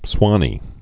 (swŏnē)